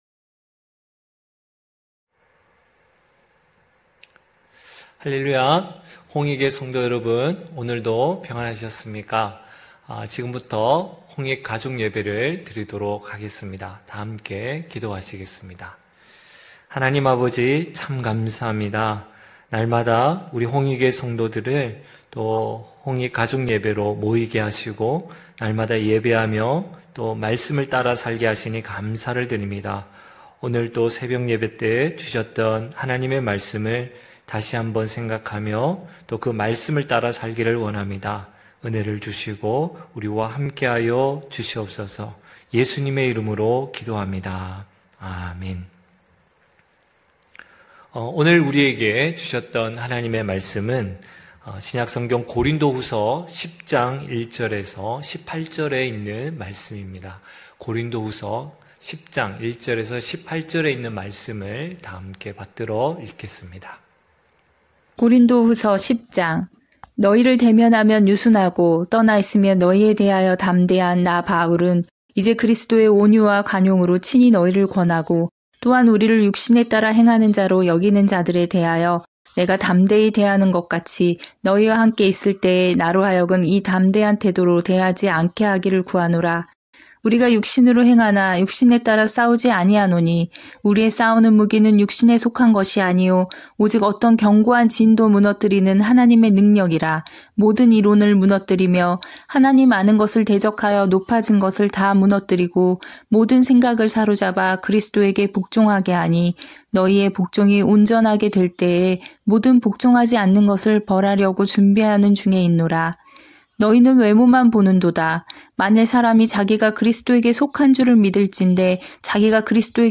9시홍익가족예배(10월8일).mp3